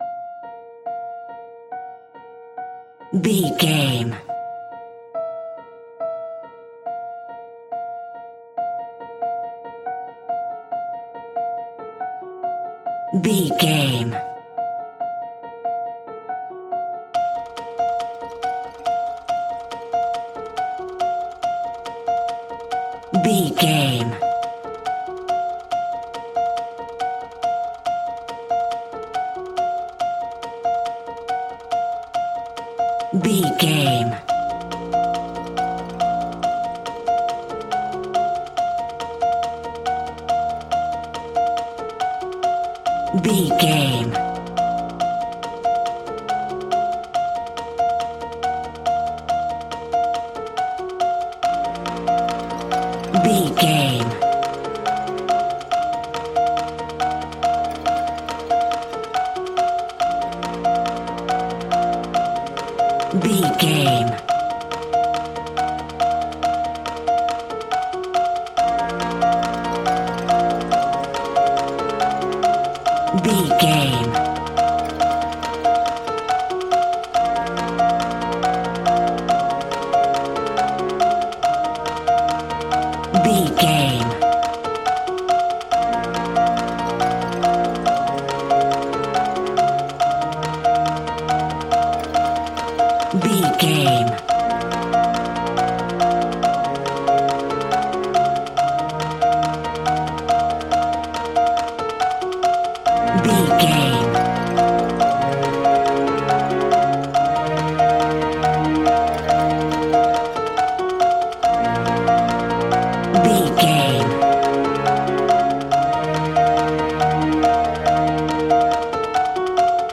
In-crescendo
Thriller
Aeolian/Minor
B♭
scary
tension
ominous
dark
suspense
eerie
piano
percussion
strings
cymbals
gongs
viola
french horn trumpet
taiko drums
timpani